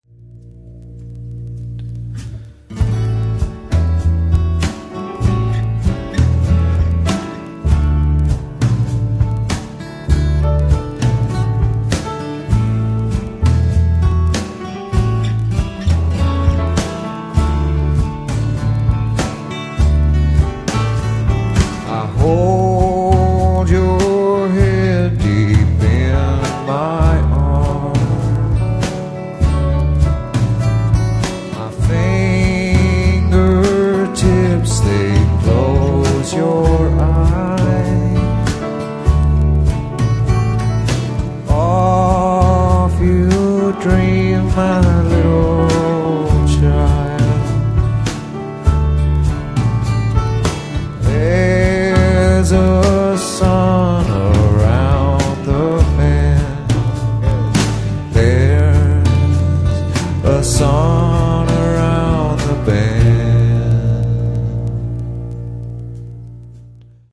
Una bella canción de cuna de una de mis bandas favoritas